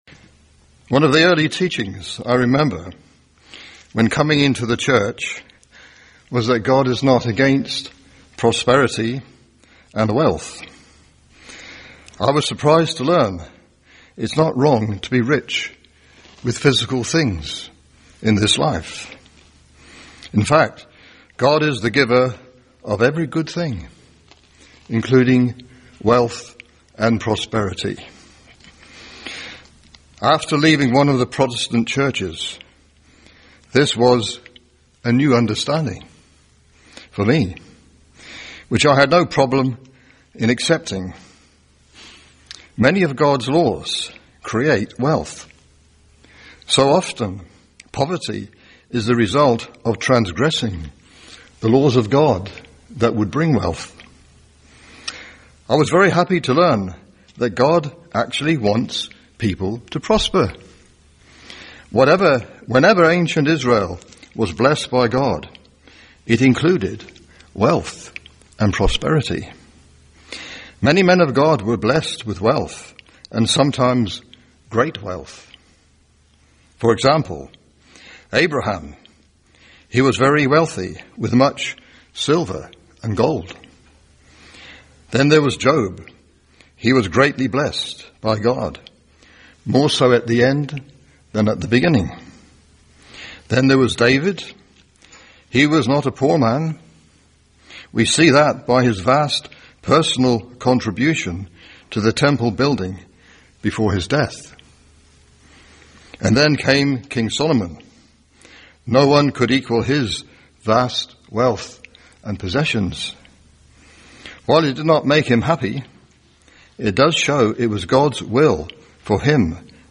Sermons – Page 116 – Church of the Eternal God